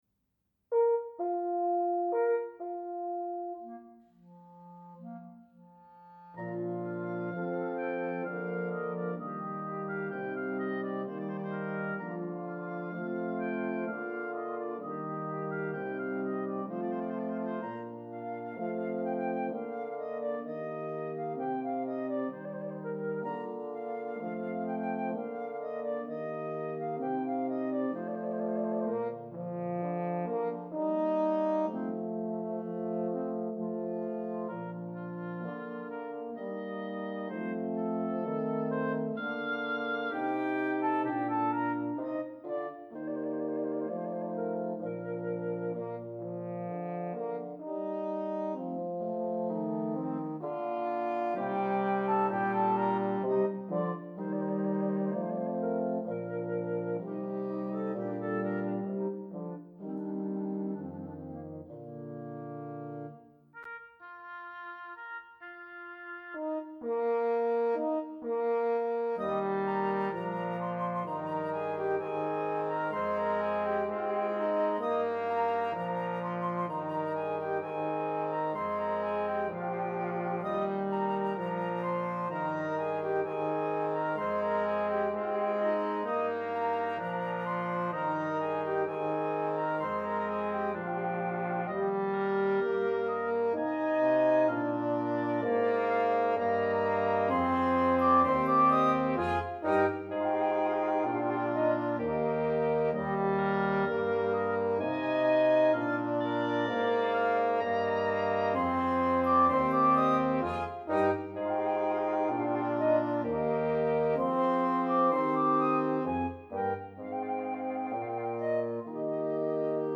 Wind Quintet
Instrumentation: Flute, Oboe, Clarinet Bb,
Horn in F, Bassoon